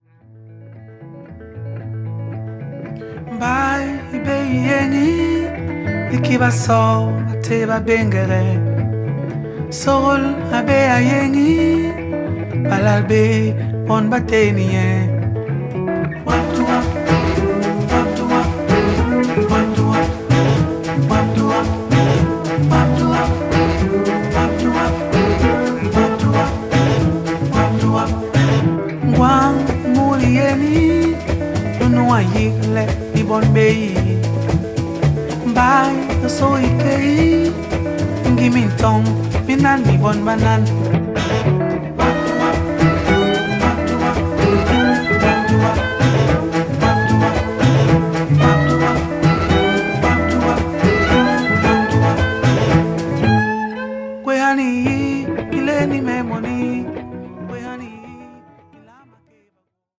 アフロ、フォーク、ブルースが時にはファンキーに、時には静かに交差するフォーキーな好盤です。